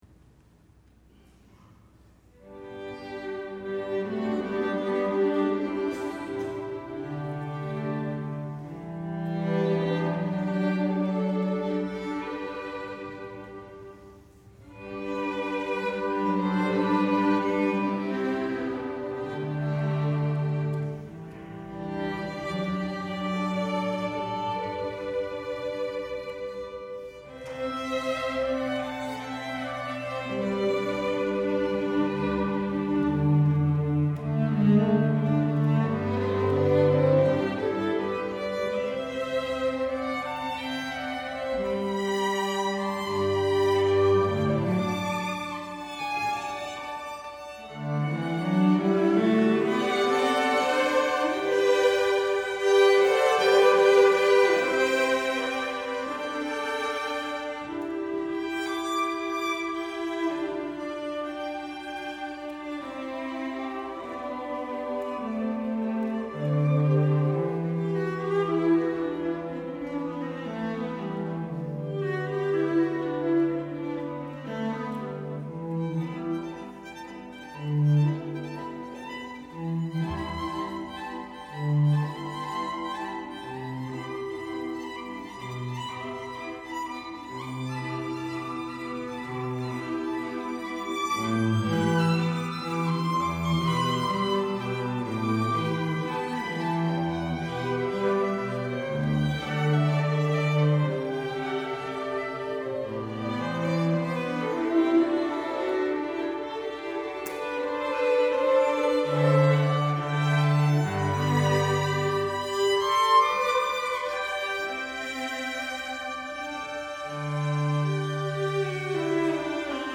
for String Quartet (2014)